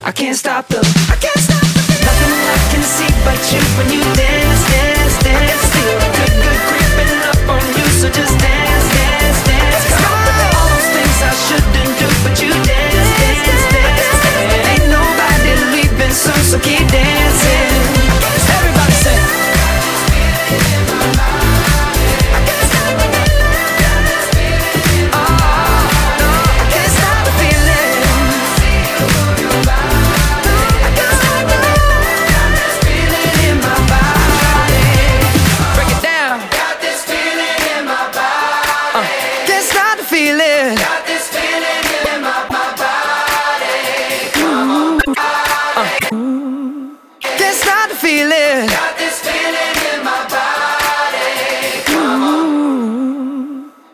• Качество: 192, Stereo
dance